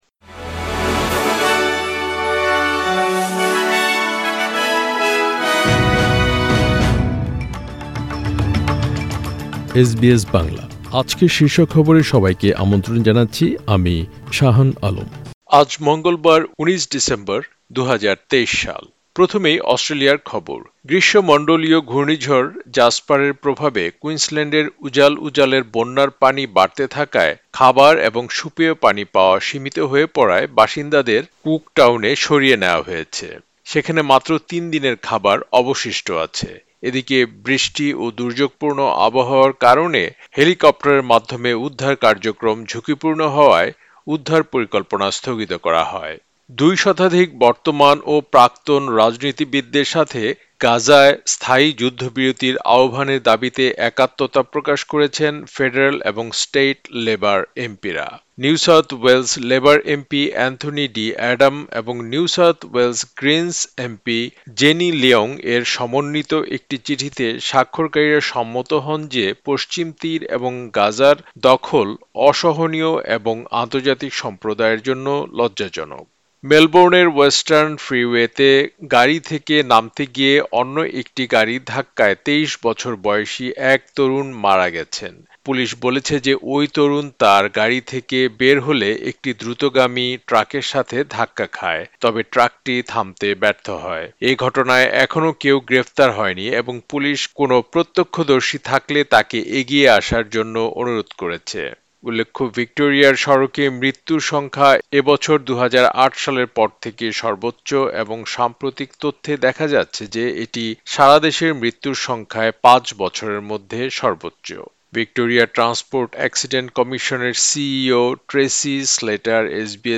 এসবিএস বাংলা শীর্ষ খবর: ১৯ ডিসেম্বর, ২০২৩